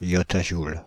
Ääntäminen
France (Île-de-France): IPA: /jɔ.ta.ʒul/